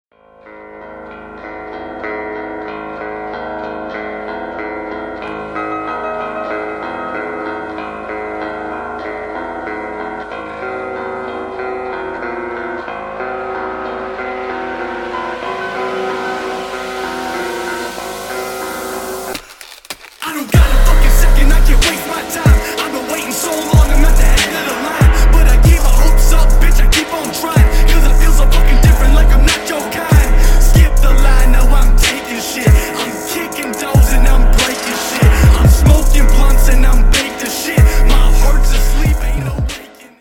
• Качество: 160, Stereo
мужской вокал
громкие
Хип-хоп
Bass
Alternative Hip-hop
Cloud Rap
агрессивные